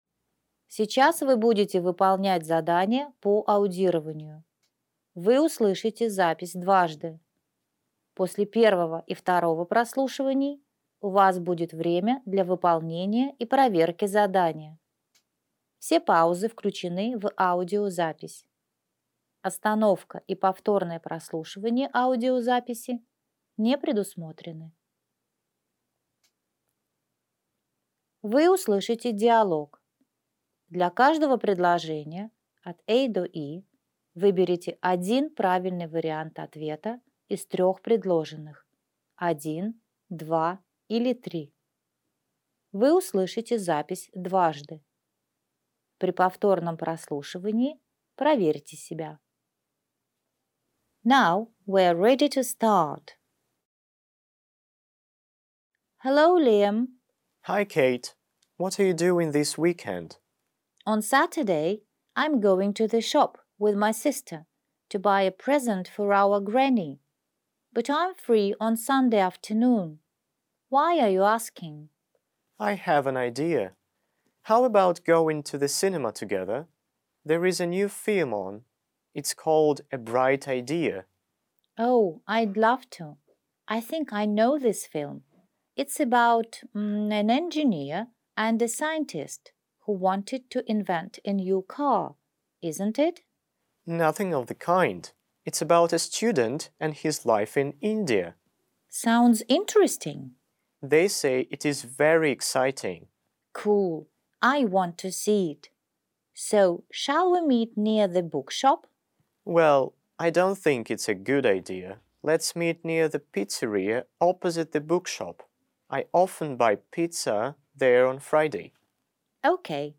Работа состоит из 4 заданий Готовые тренировочные работы ВПР по всем предметам Скачать вариант Скачать ответы Скачать аудио Интересные задания: Вы услышите диалог.